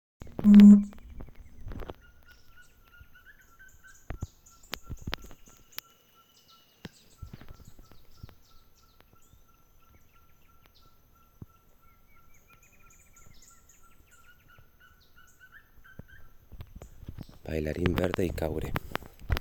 Bailarín Verde (Piprites chloris)
Nombre en inglés: Wing-barred Piprites
Localidad o área protegida: Parque Nacional Iguazú
Condición: Silvestre
Certeza: Observada, Vocalización Grabada